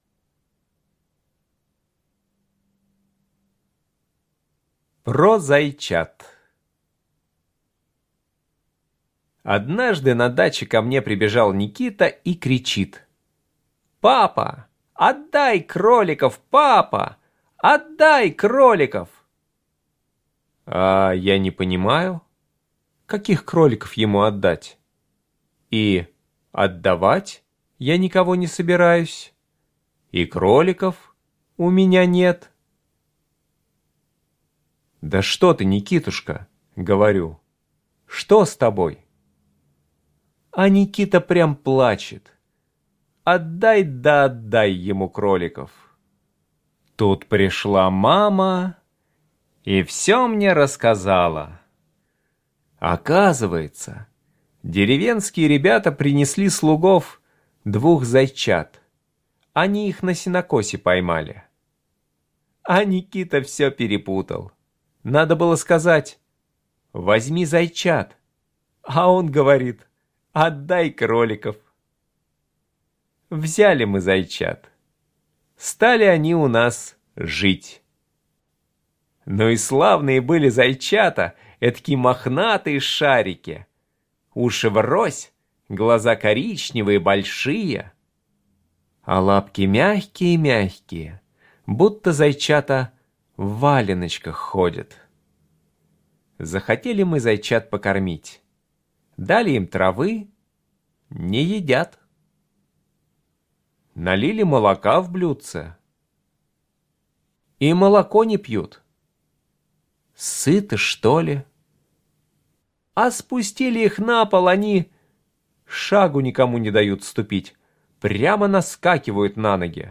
Слушайте Про зайчат - аудио рассказ Чарушина Е.И. Принесли как-то ребята с сенокоса двух маленьких зайчат, которые могли только молочко сосать.